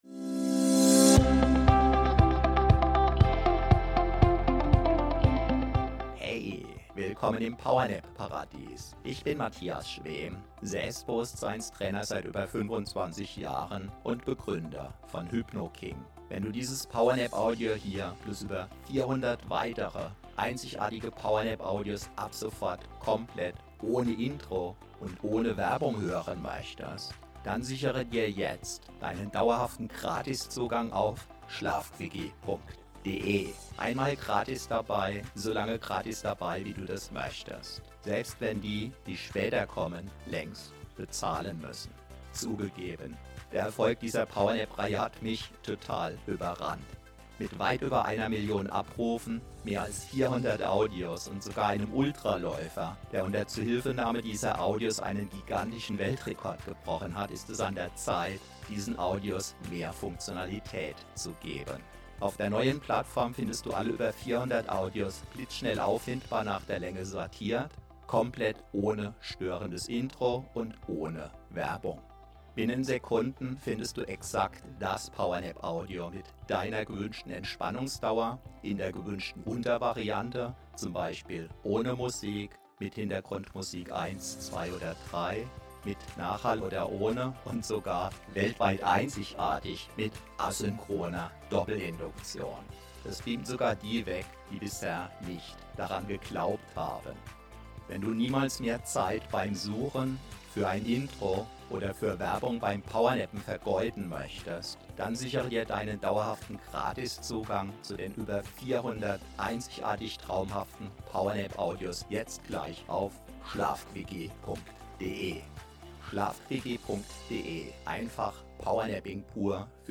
PowerNap 8 min ACHTUNG: Extrem STARK asynchrone Doppelinduktion!